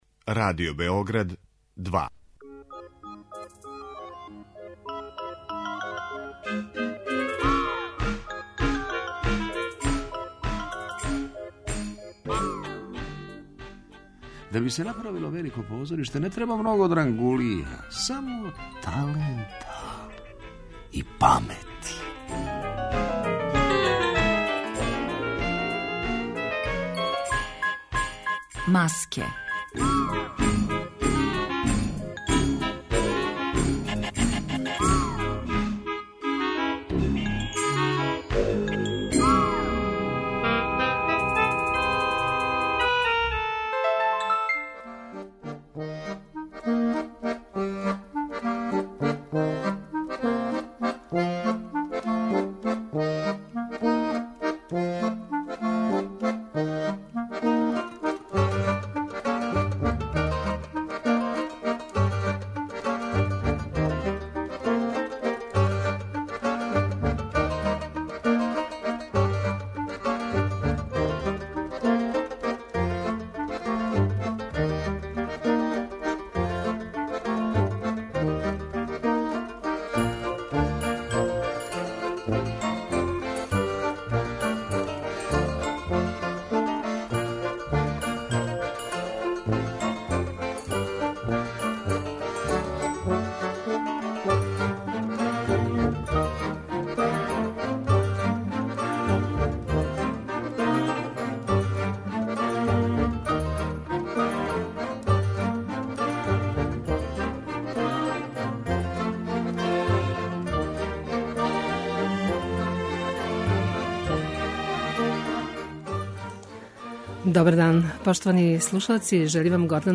Емисија о позоришту